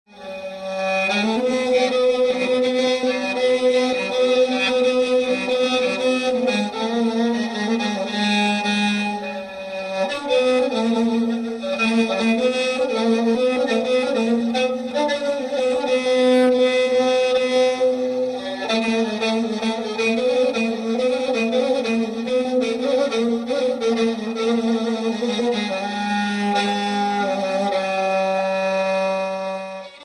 قیچک
قِـیچَک یا غیژک یکی از سازهای زهی آرشه ای در موسیقی ایرانی است که همانند کمانچه با آرشه یا کمان نواخته می‌شود.
قیچک در اندازه های متنوع جهت کاربردهای متفاوتی ساخته می شود و وسعت صدای آن حدود 3 اکتاو می باشد.
gheychak.mp3